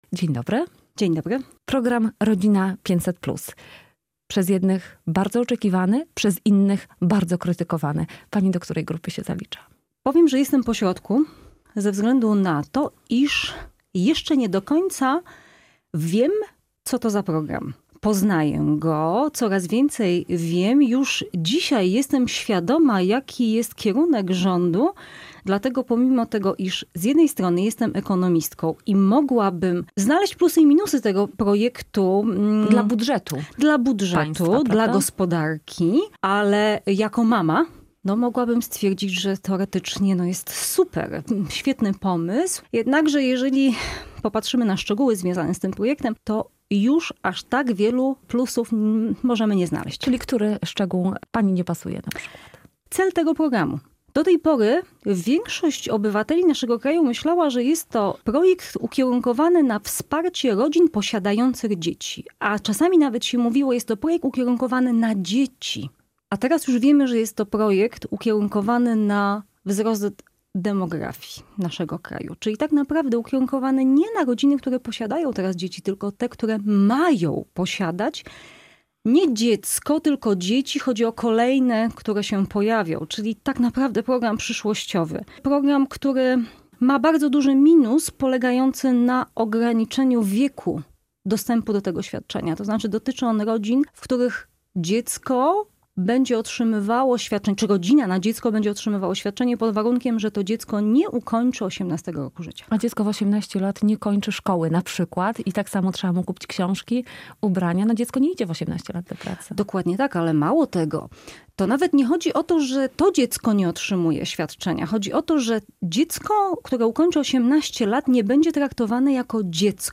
ekonomistka